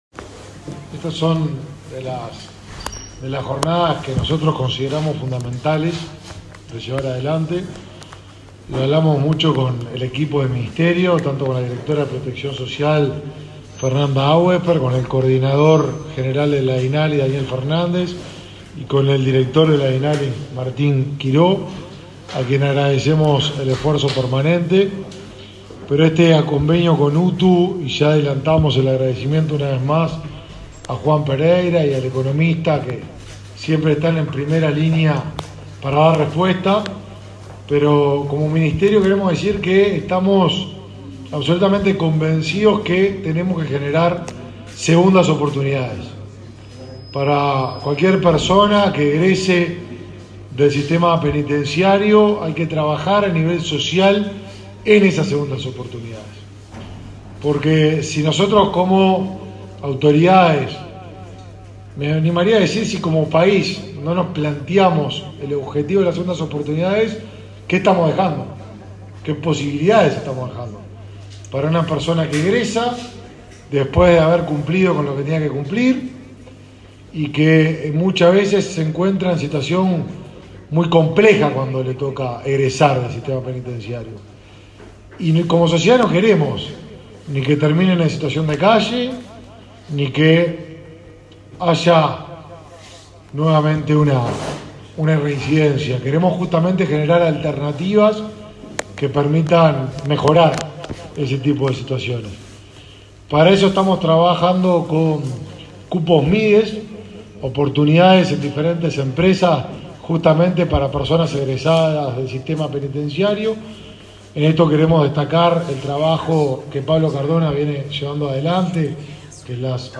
Palabra de autoridades de Mides y UTU
Palabra de autoridades de Mides y UTU 11/07/2022 Compartir Facebook X Copiar enlace WhatsApp LinkedIn En el marco del convenio de capacitación en multioficios entre el Ministerio de Desarrollo Social (Mides) y la UTU, se lanzaron este lunes 11 cursos de formación sobre galletería para egresados del sistema penitenciario. El ministro Martín Lema y el director general de UTU, Juan Pereyra, destacaron la importancia de esa formación.